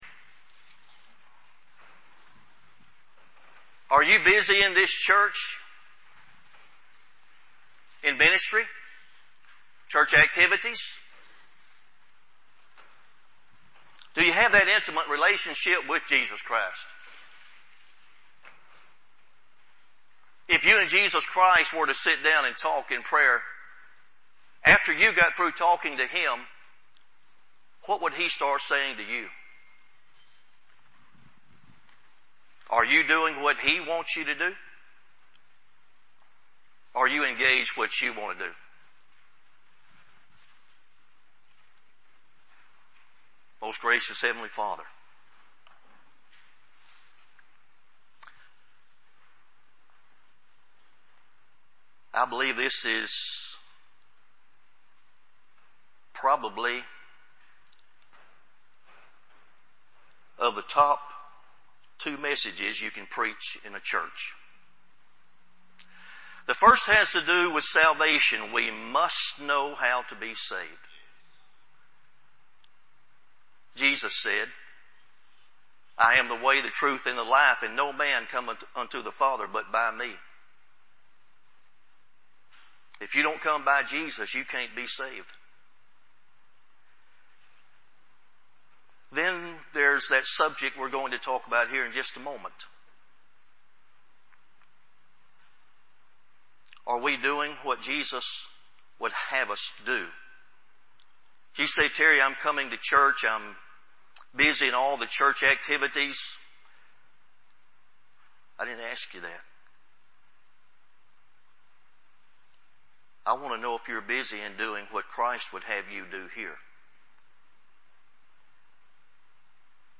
SERMON-11-3-CD.mp3